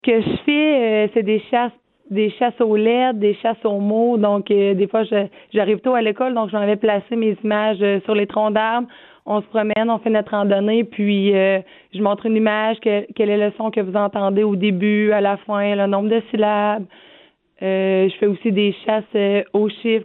Écoutez-là décrire quelques-unes de ses activités pédagogiques :